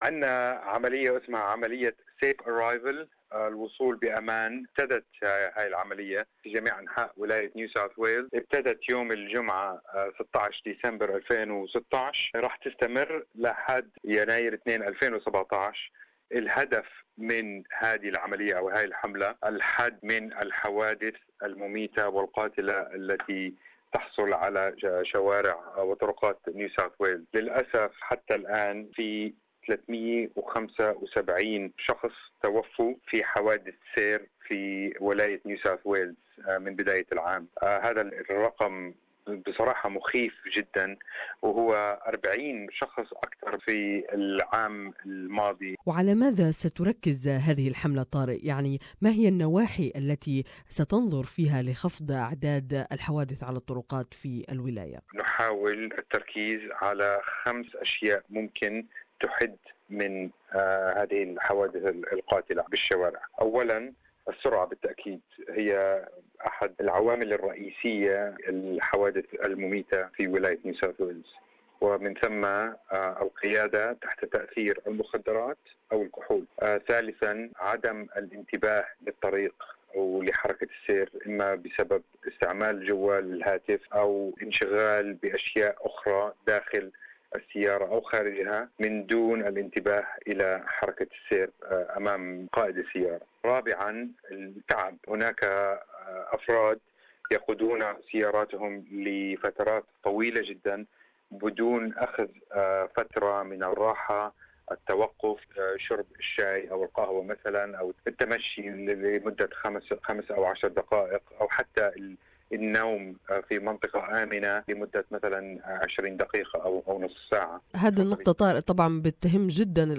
NSW police launched "Arrive Safely" campaign to reduce road accidents and fatalities More in this interview